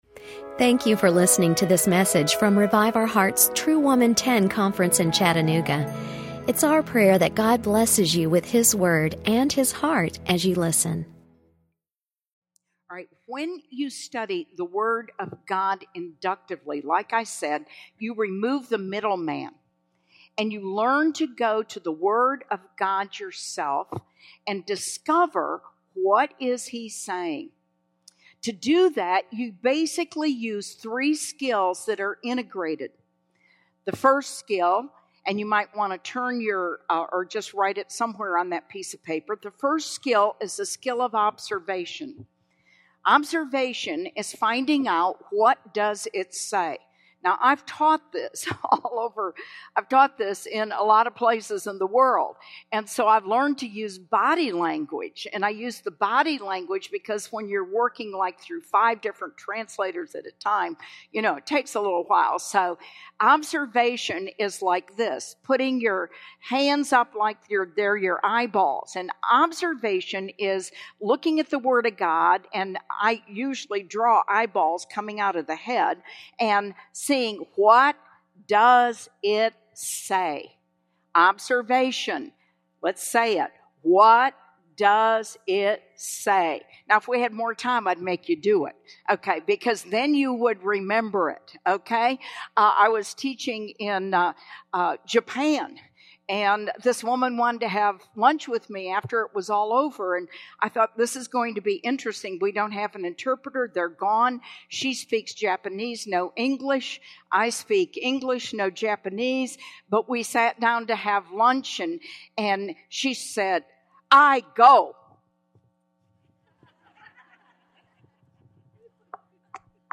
Becoming a Woman of Greater Faith | True Woman '10 Chattanooga | Events | Revive Our Hearts
In this "hands on" seminar, you will not only study the subject, you will learn inductive study skills that will help you become a woman of unshakable faith.